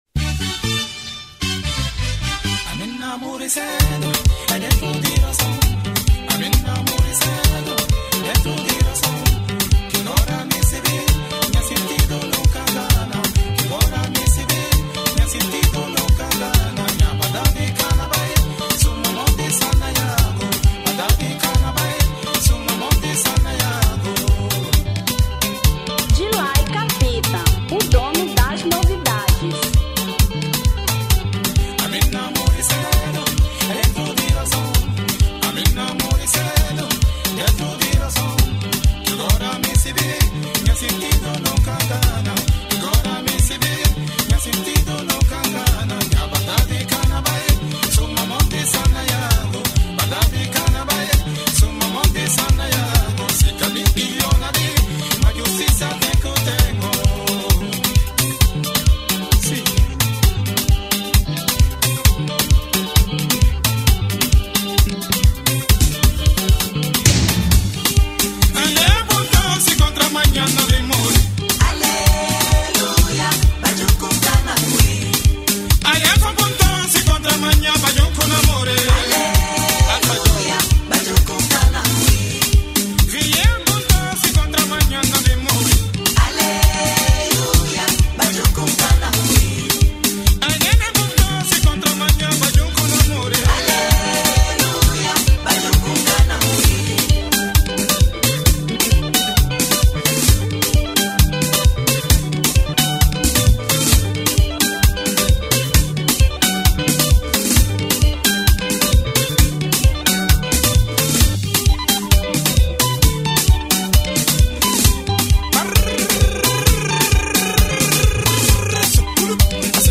Rumba 2006